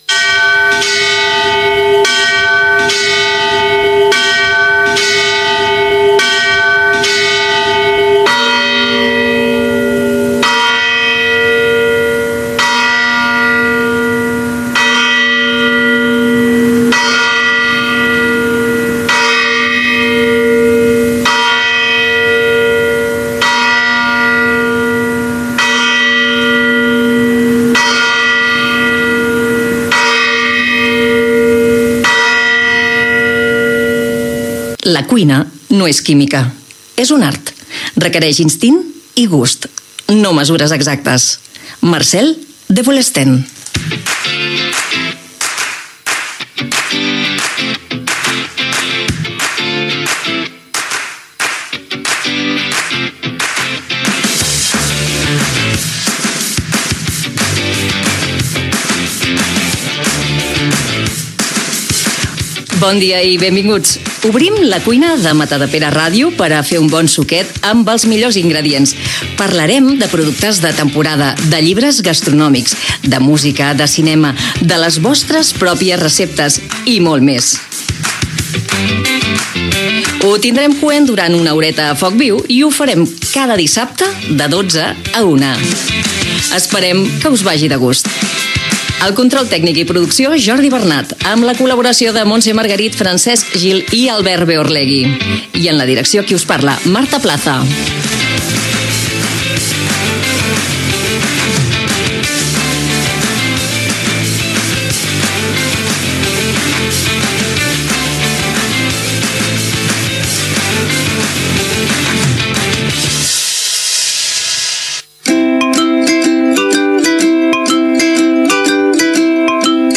Primera edició del programa de cuina i gastronomia. Cita, sintonia, sumari i secció dels productes de temporada
Entreteniment
FM